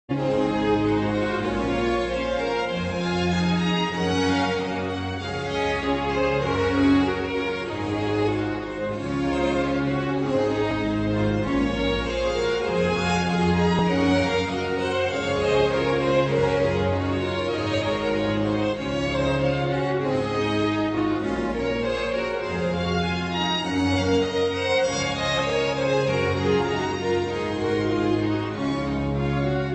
• Classical Ringtones